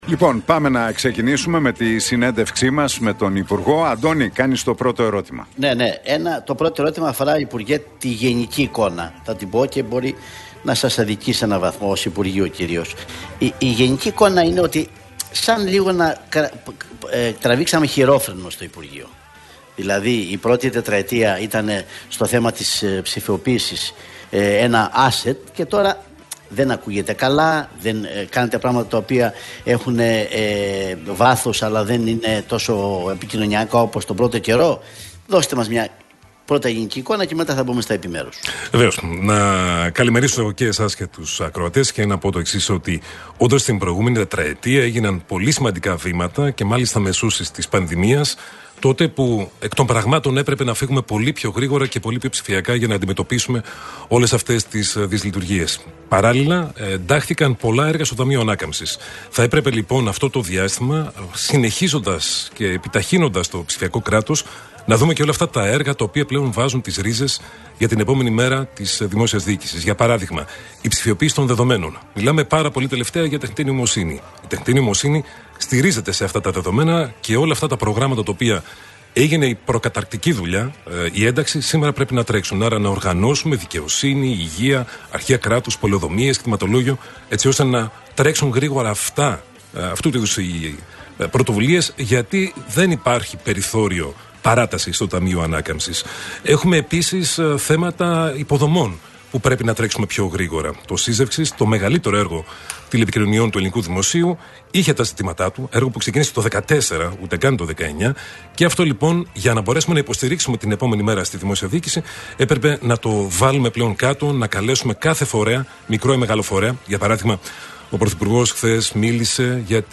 Ο Δημήτρης Παπαστεργίου στο στούντιο του Realfm 97,8: Αυτές είναι οι νέες προσθήκες στο wallet - Τι είπε για το MyGreece και τα ψηφιακά εισιτήρια